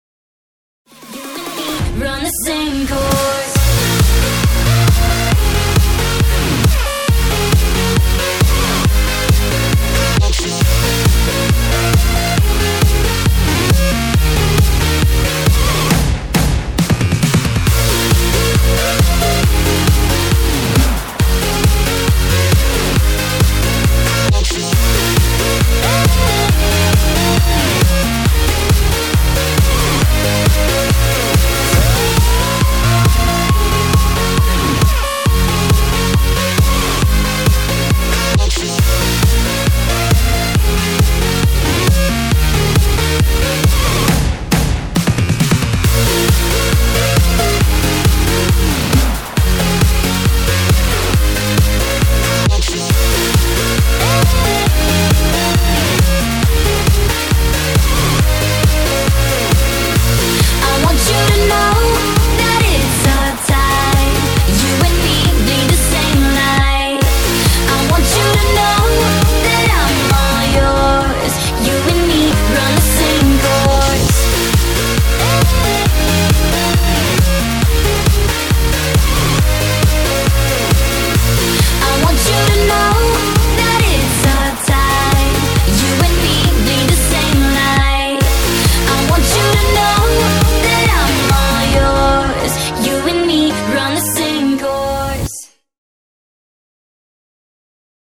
136 bpm: 1,5 min